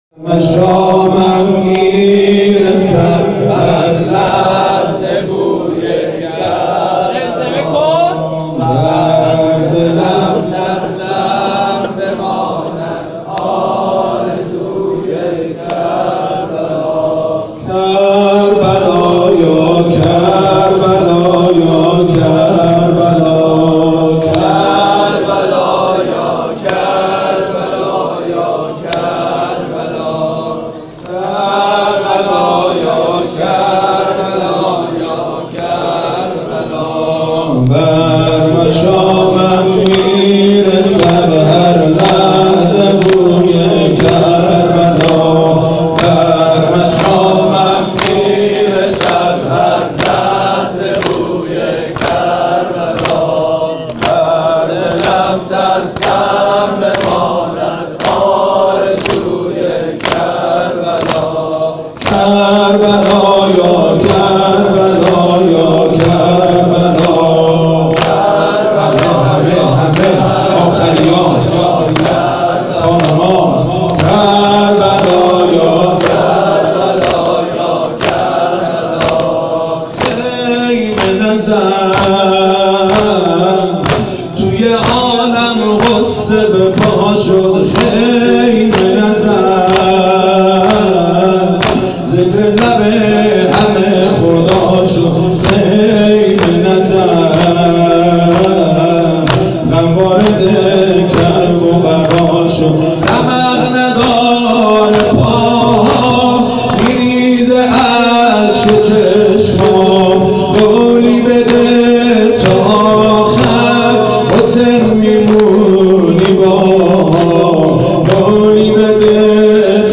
توضیحات: هیئت محبان الائمه بنجاری ها
جلسات هفتگی